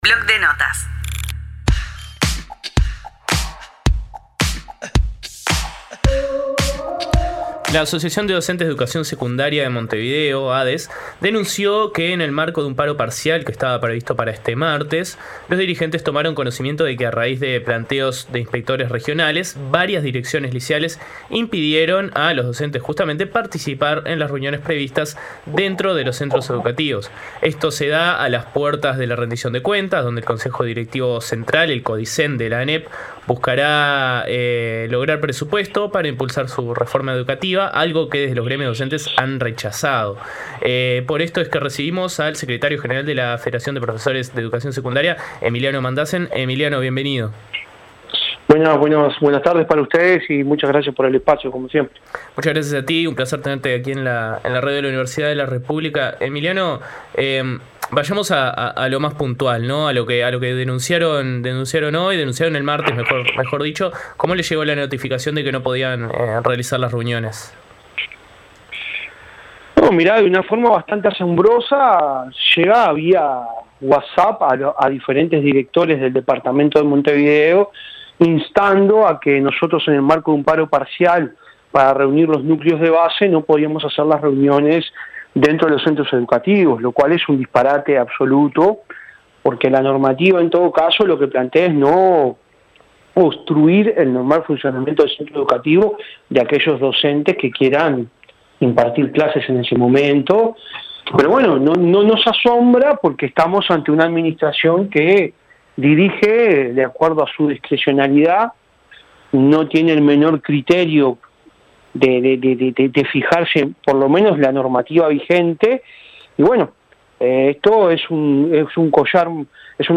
Comunidad Udelar, el periodístico de UNI Radio.